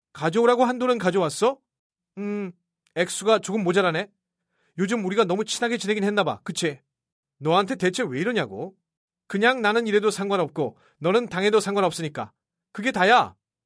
들려드린 목소리는 모두 AI로 복제된